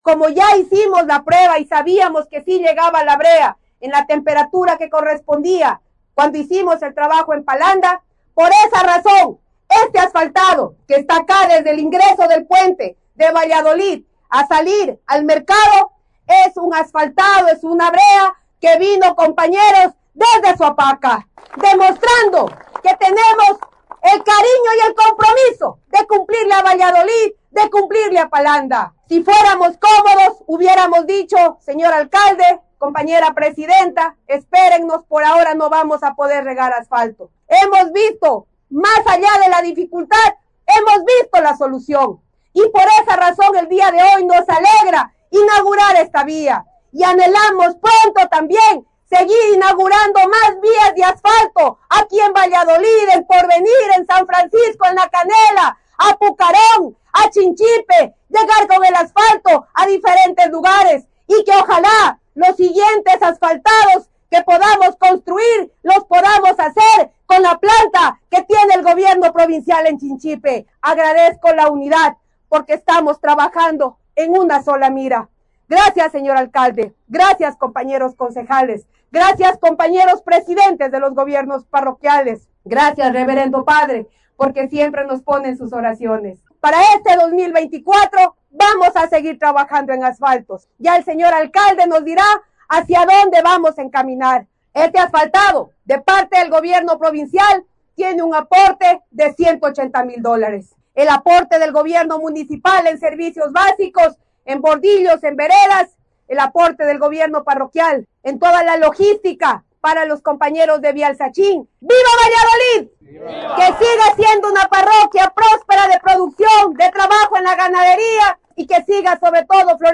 KARLA REÁTEGUI, PREFECTA ENTREGA ASFALTO VALLADOLID
KARLA-REATEGUI-PREFECTA-ENTREGA-ASFALTO-VALLADOLID.mp3